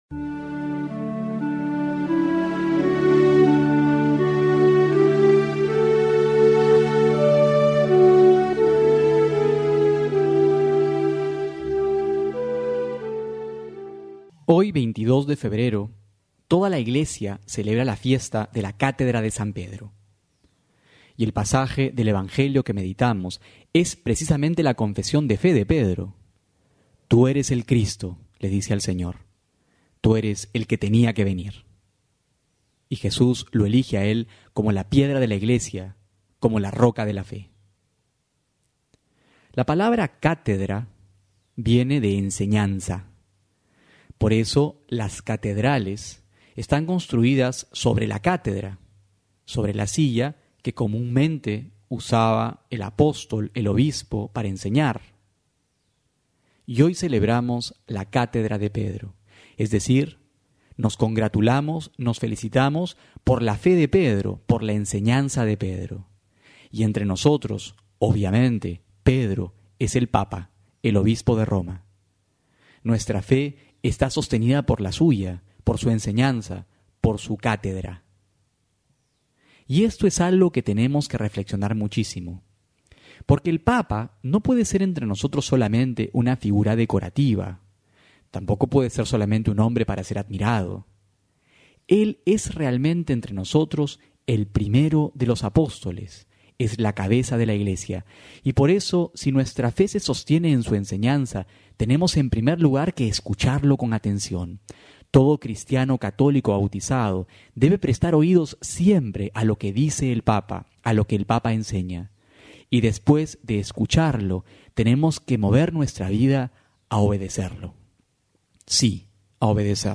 Homilía para hoy: Mateo 6,1-6.16-18
febrero22-12homilia.mp3